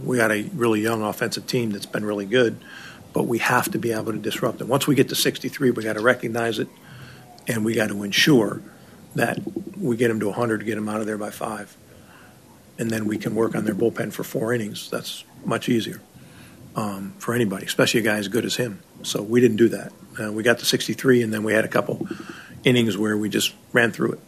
Brewers manager Pat Murphy talked about his team trying to run up Skenes’ pitch count.